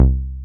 T B303贝司 " T B3贝司 23 - 声音 - 淘声网 - 免费音效素材资源|视频游戏配乐下载
原始的Roland TB303机器通过Manley话筒前置采样。